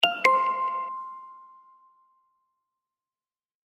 notification sound for notify()
notification.mp3